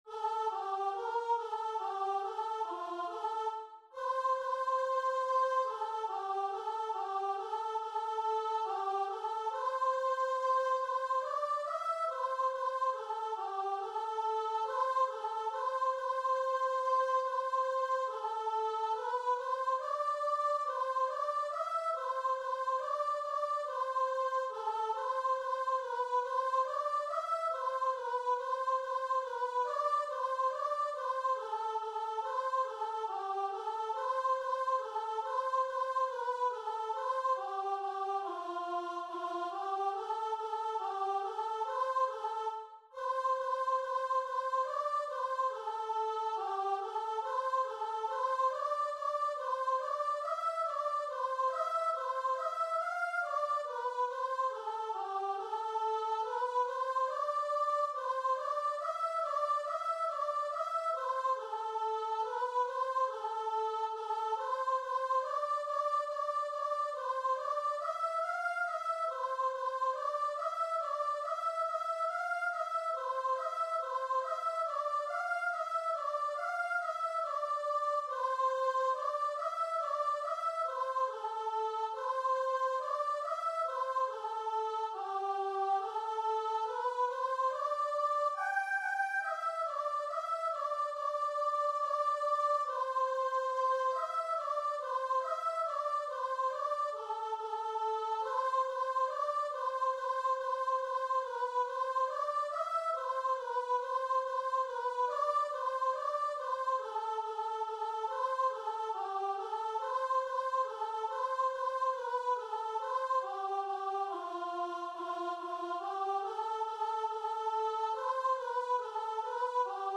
Free Sheet music for Choir
C major (Sounding Pitch) (View more C major Music for Choir )
Choir  (View more Easy Choir Music)
Christian (View more Christian Choir Music)